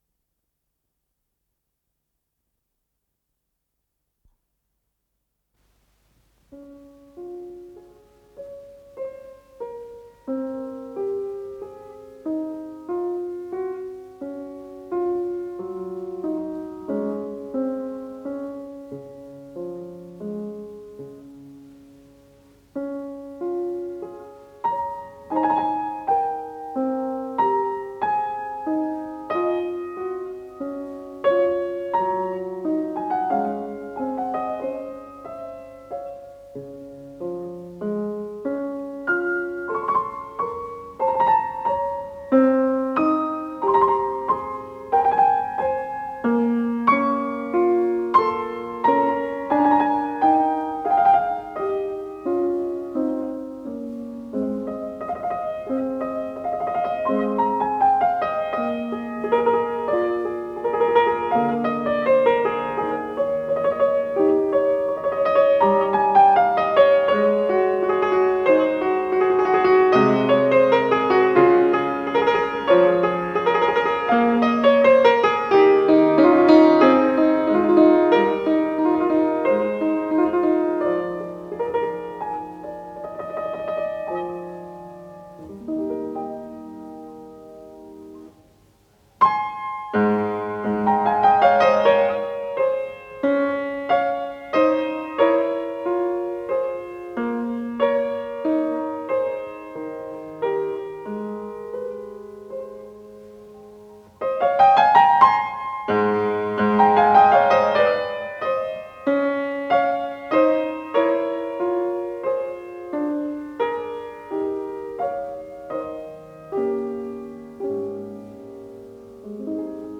с профессиональной магнитной ленты
Адажио
фортепиано